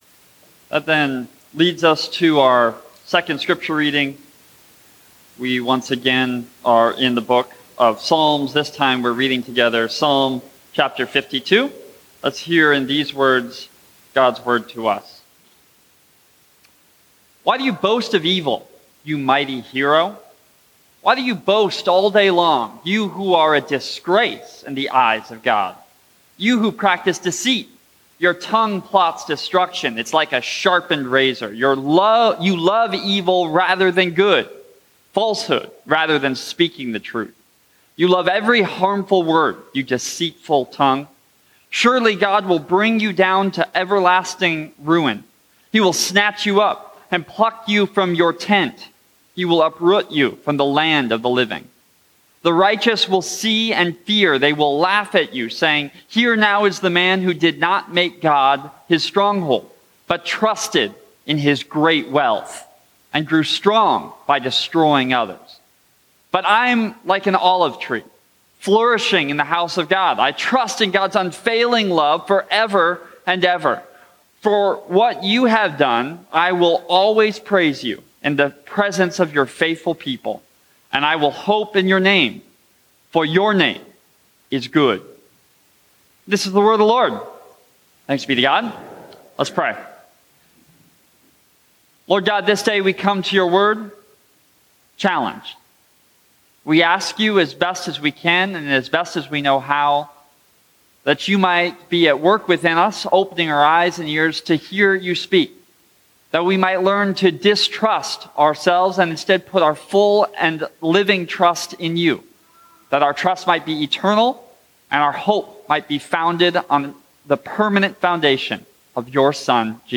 The 8:50 worship service at First Presbyterian Church in Spirit Lake.